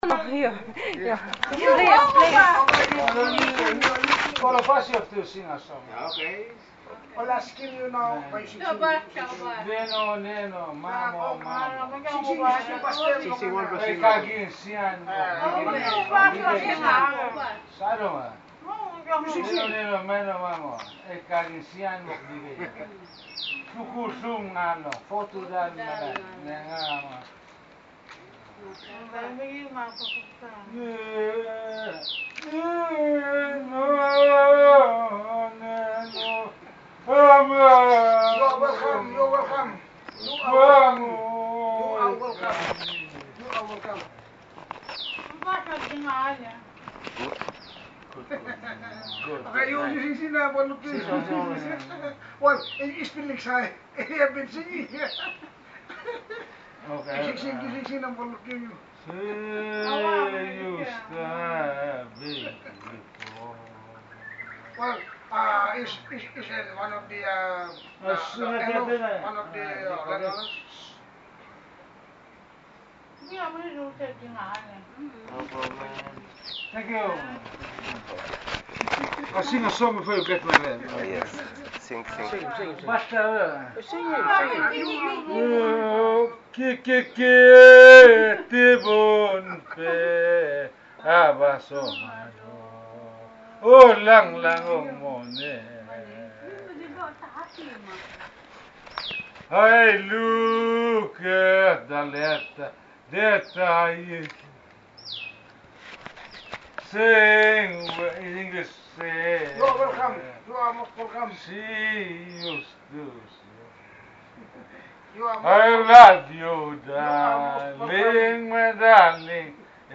drunked-papuas-song.MP3